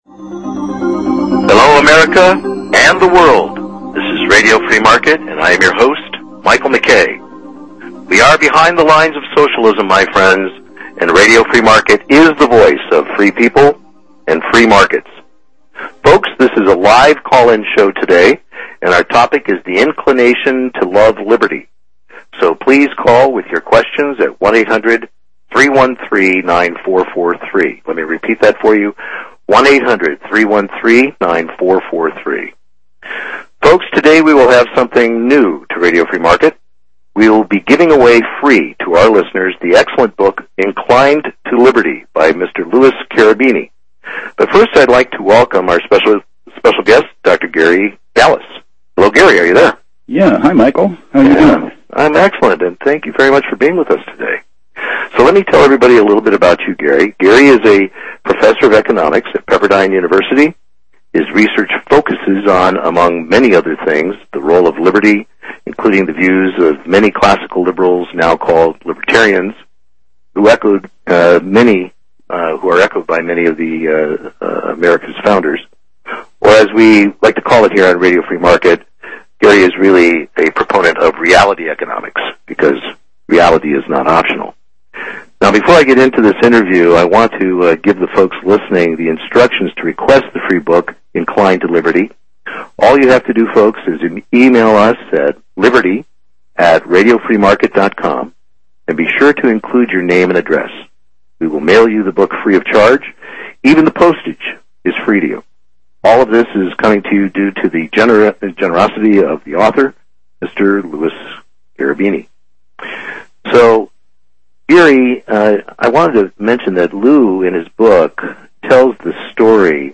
a book review and interview